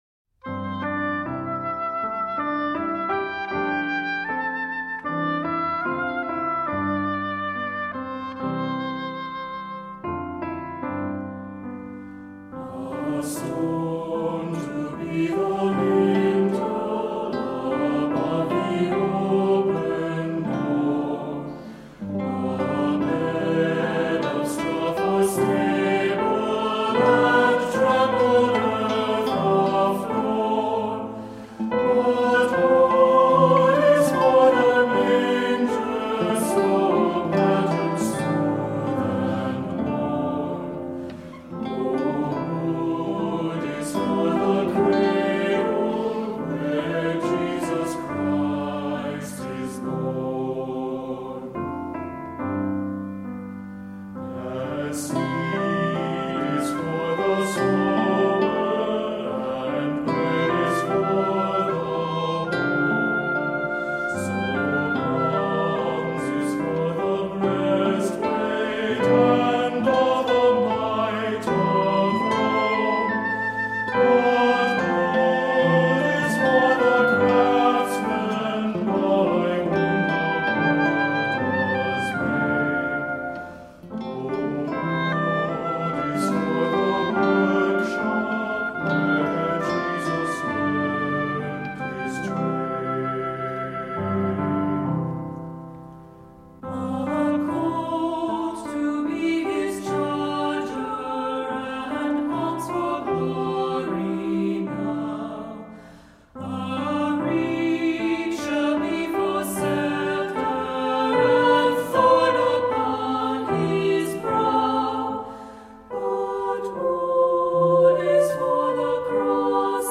Voicing: SAB; Descant; opt. Assembly